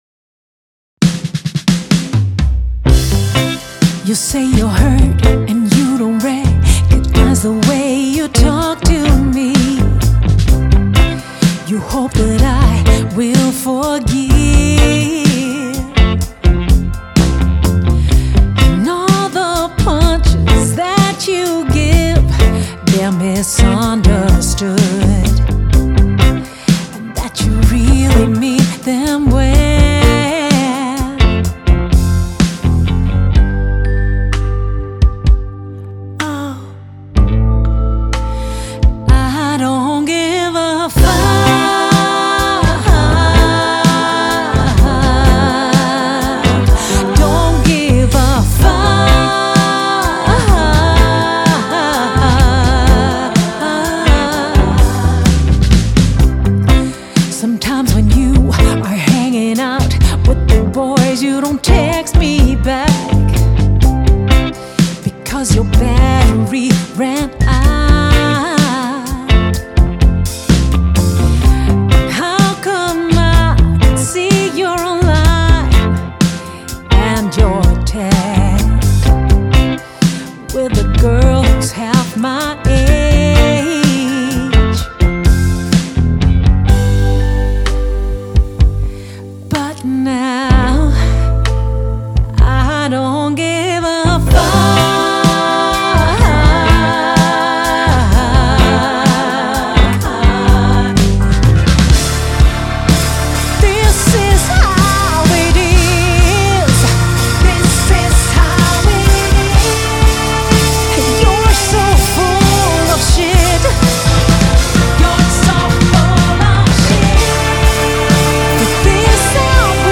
• Funk
• Pop
Alt er håndspillet og rammer både krop, hoved og sjæl.